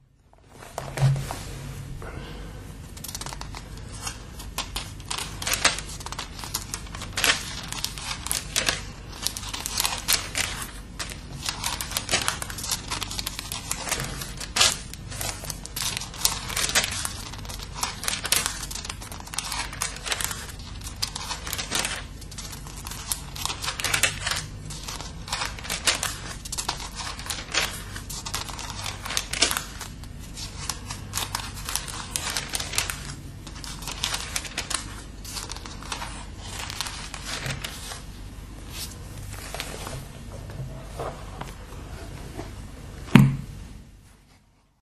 描述：翻开教会在1942年送给我父亲的《圣经》（荷兰语译本）中的《列王纪元》。